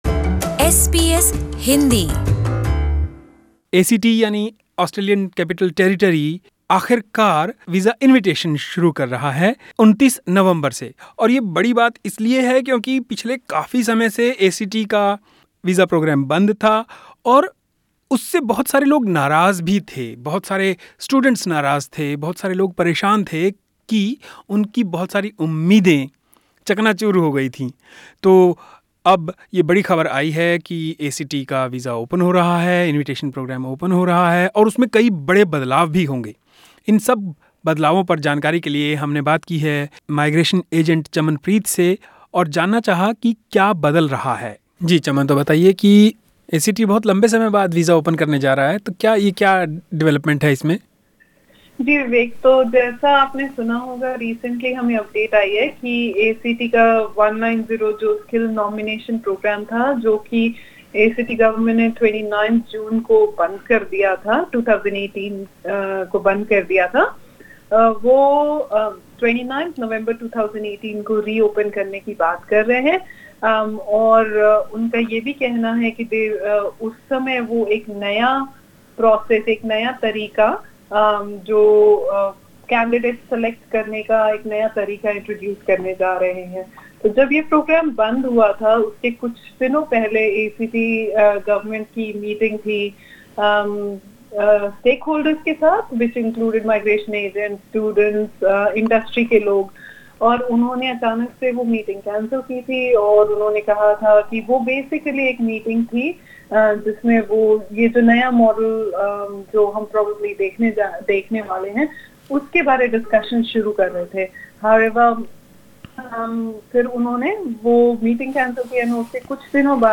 Take a listen to this complete interview to know the possibilities and the scope of the long-awaited program in detail.